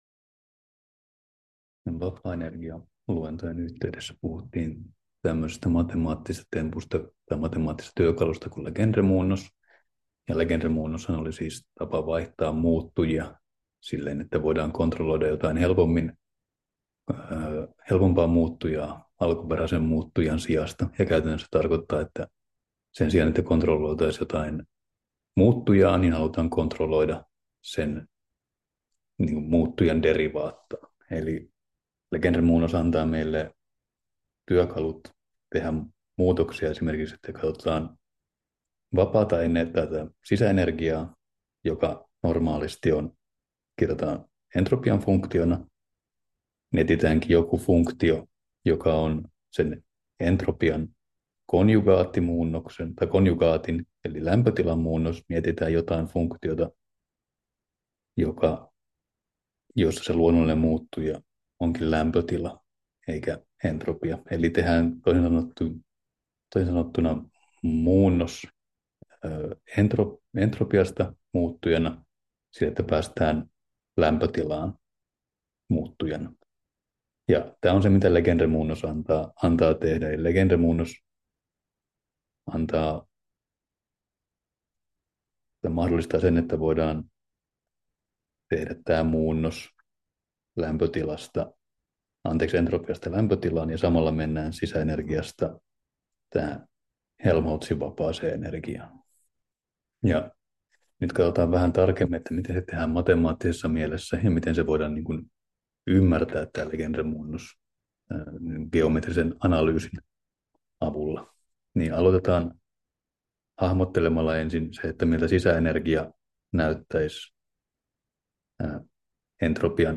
Luento 6: Legendre-muunnos